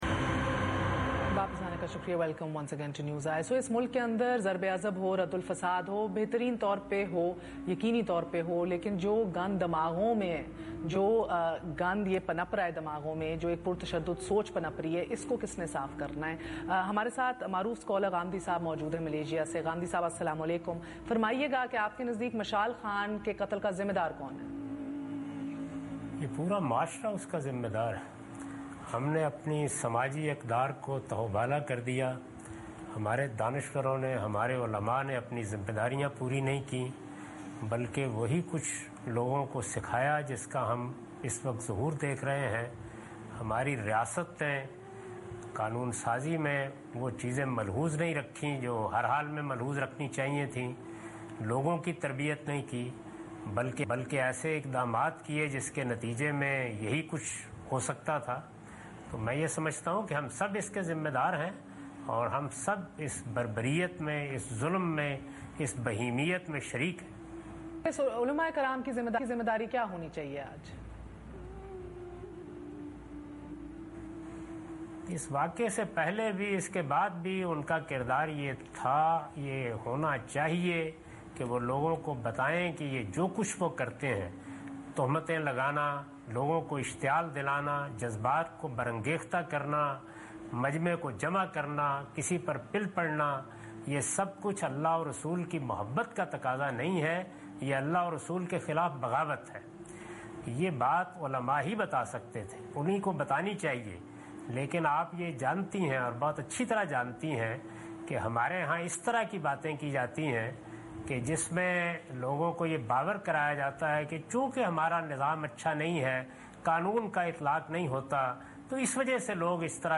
Category: TV Programs / Questions_Answers /